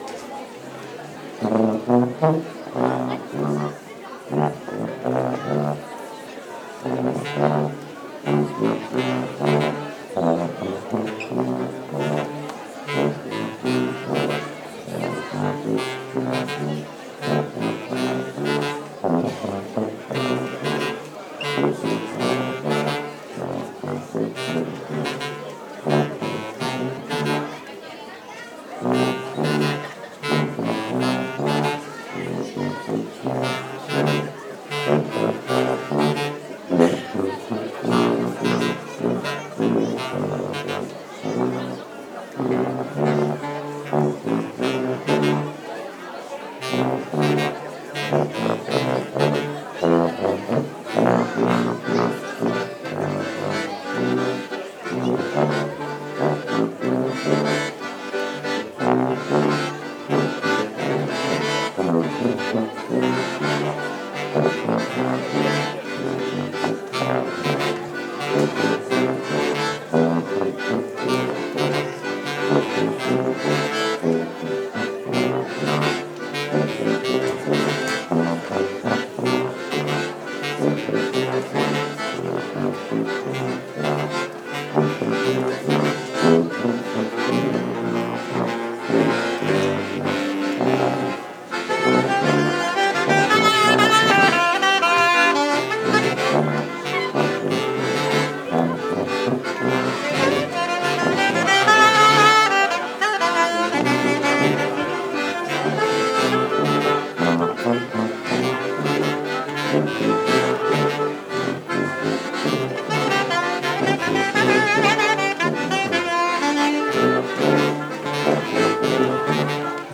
04_baleu-orchestre.mp3